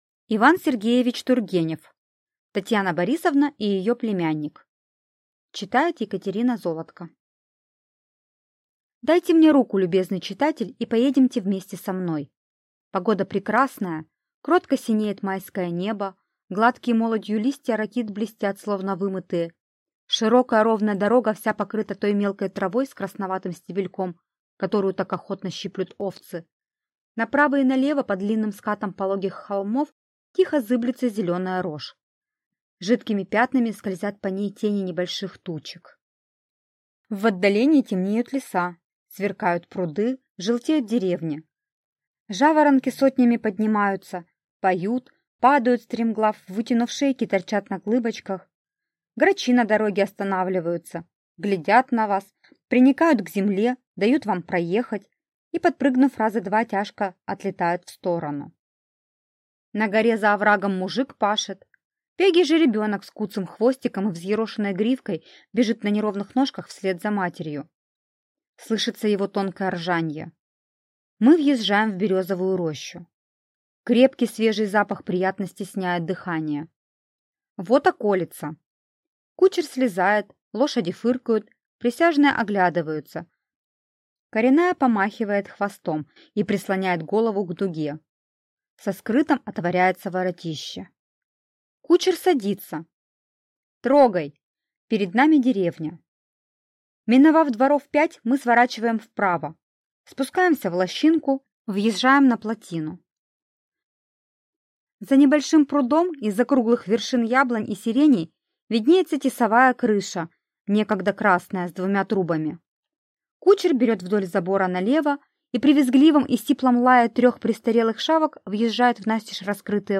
Аудиокнига Татьяна Борисовна и её племянник | Библиотека аудиокниг